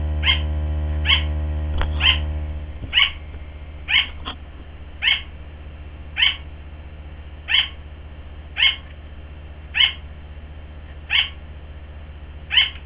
Jerry's mating bark
Jerbark12s.wav